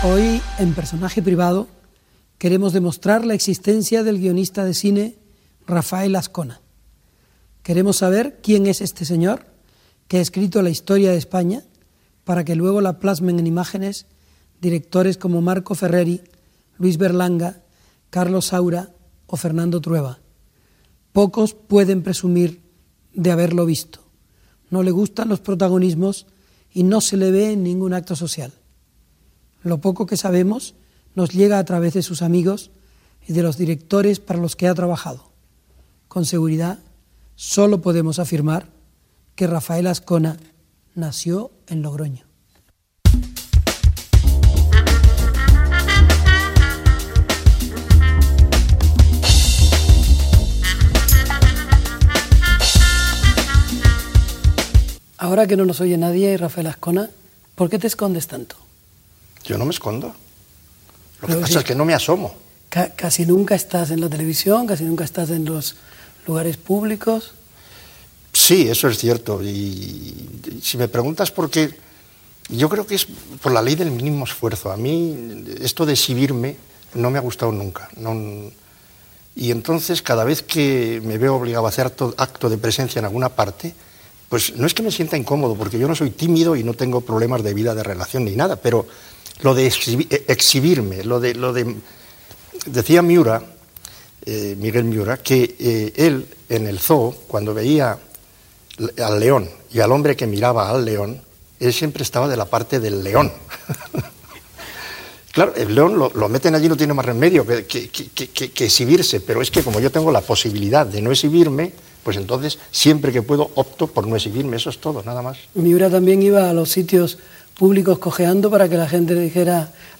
Presentació i entrevista al guionista Rafael Azcona. S'hi parla de la seva discreció, de la seva feina com a guionista i de la seva vida quotidiana